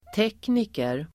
Uttal: [t'ek:niker]